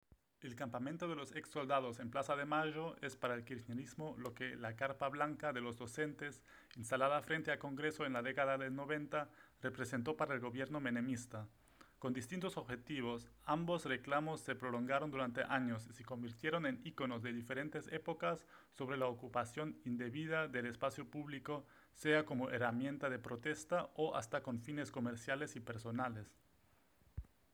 Meine Stimme in 4 Sprachbeispielen:
Sprachbeispiel Spanisch